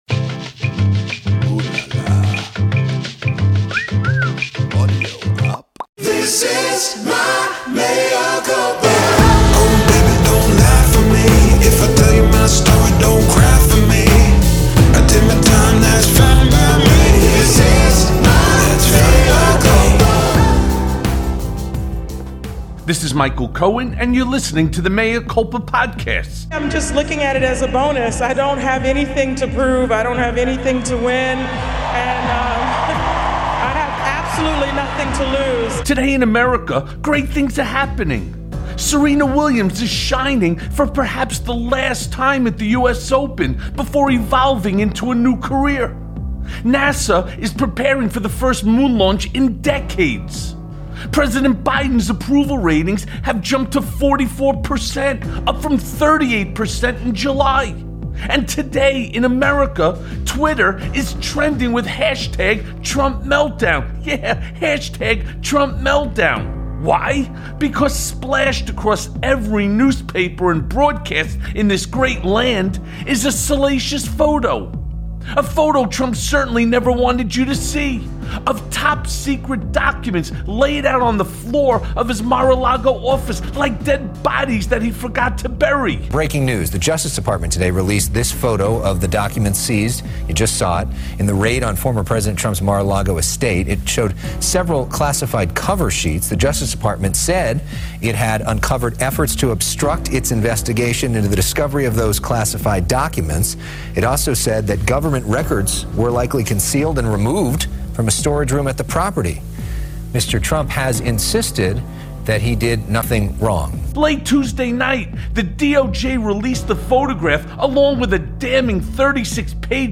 You've Got Jail! Trump Running Out of Places to Hide + A Conversation with Zerlina Maxwell
Mea Culpa welcomes political analyst, Zerlina Maxwell.